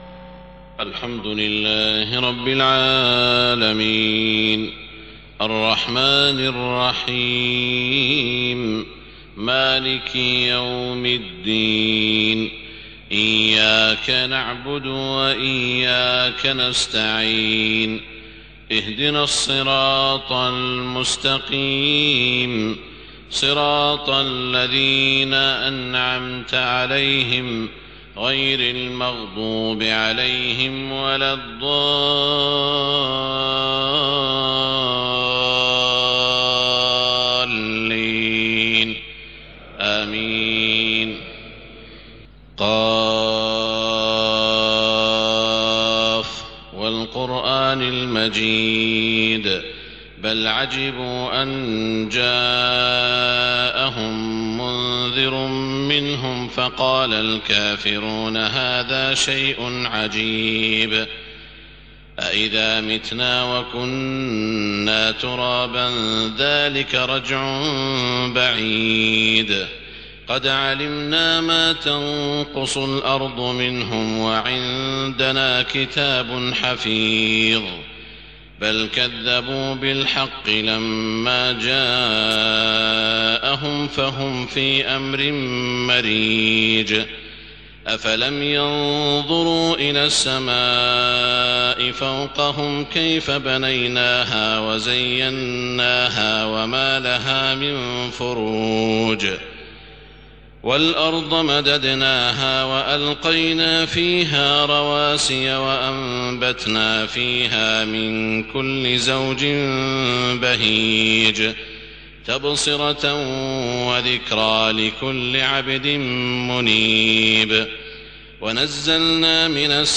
صلاة الفجر 27 ربيع الأول 1430هـ من سورة ق > 1430 🕋 > الفروض - تلاوات الحرمين